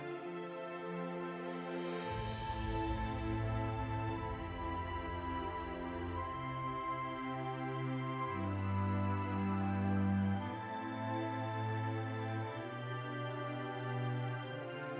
Musique multipistes.
Pistes : 6 (dont des cœurs)